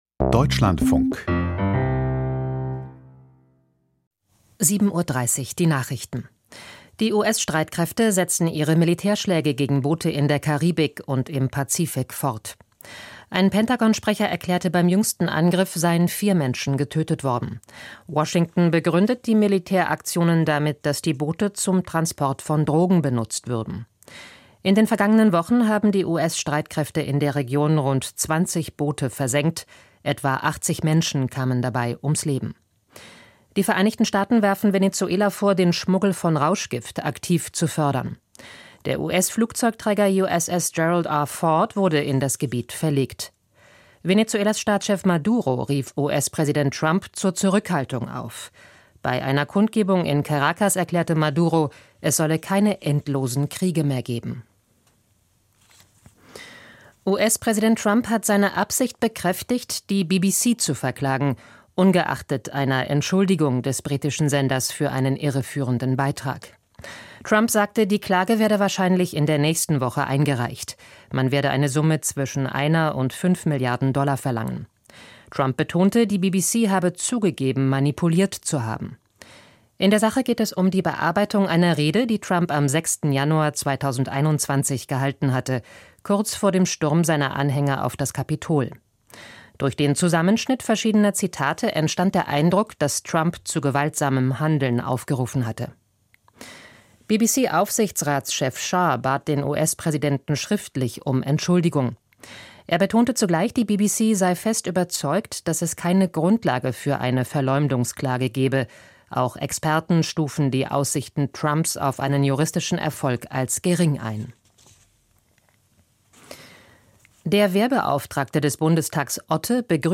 Die Nachrichten vom 15.11.2025, 07:30 Uhr